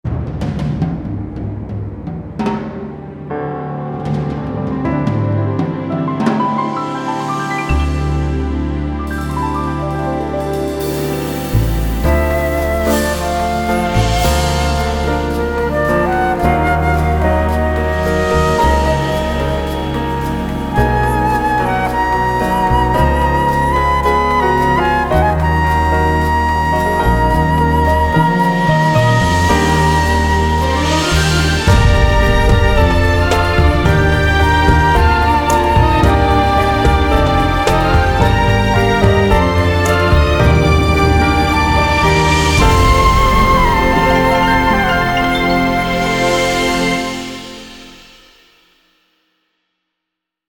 作曲・制作：「
オープニング・テーマ」「BGM